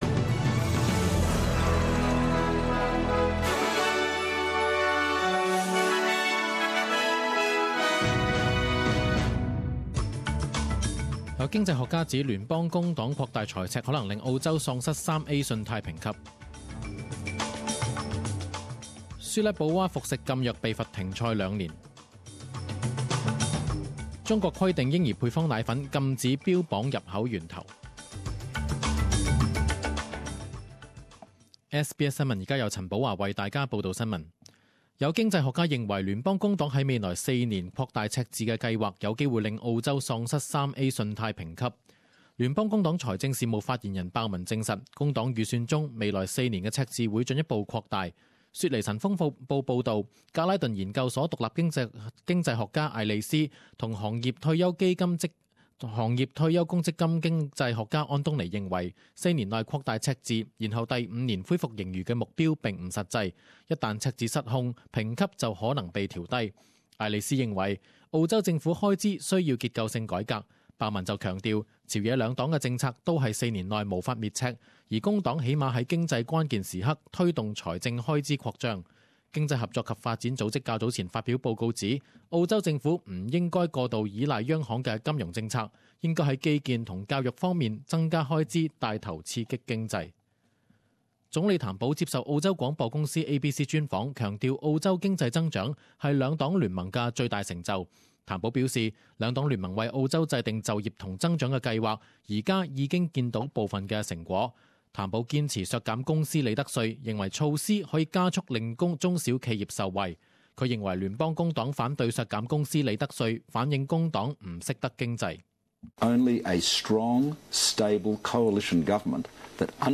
十点钟新闻报导（六月九日）